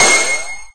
Flash1.ogg